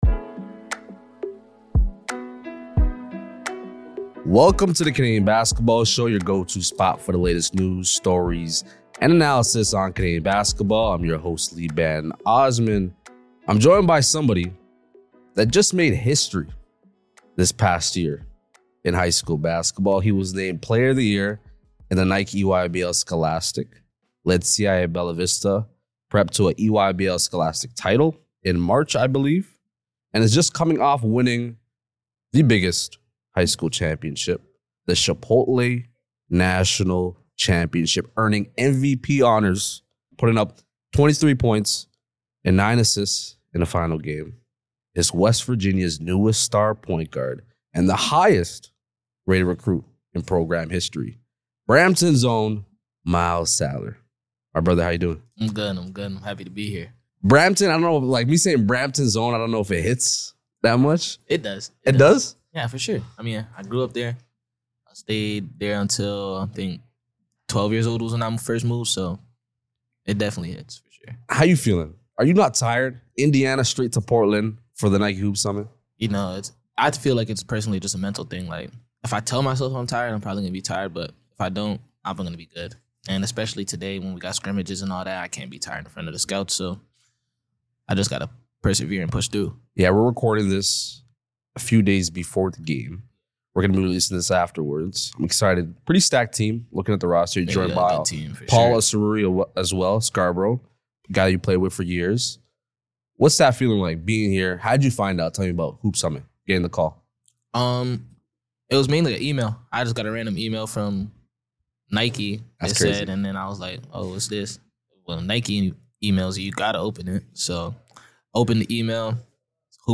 Live in Portland at the Nike Hoop Summit